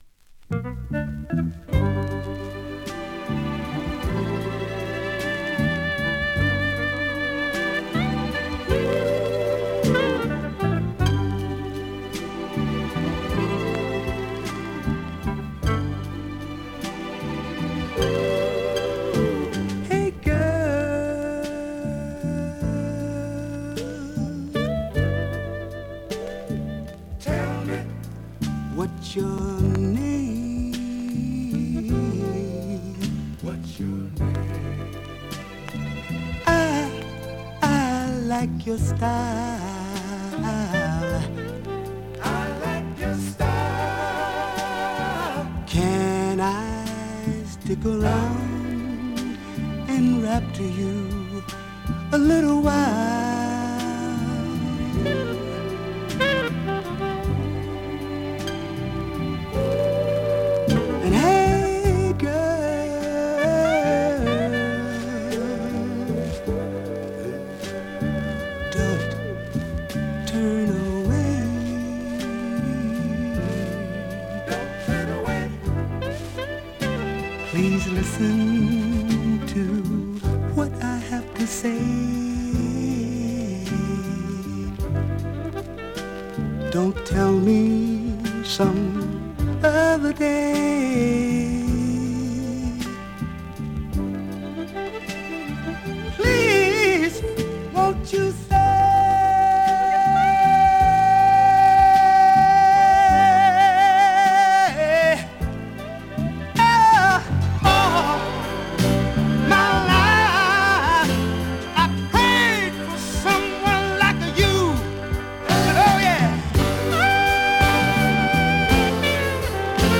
SOUL、FUNK、JAZZのオリジナルアナログ盤専門店